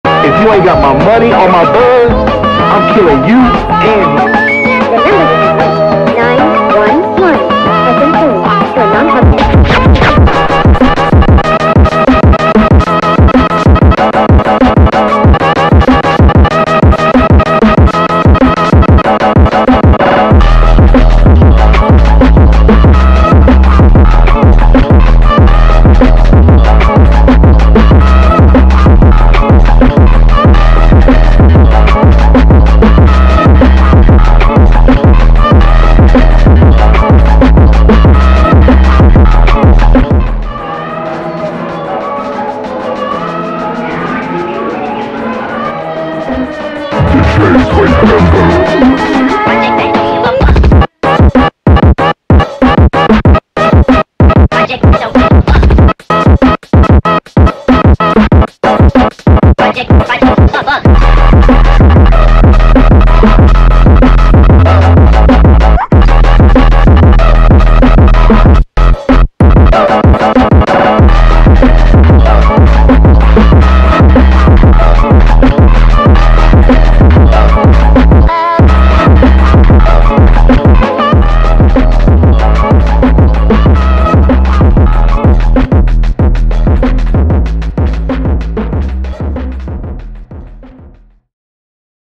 دانلود فانک با وایبی دارک با ریتمی تند
فانک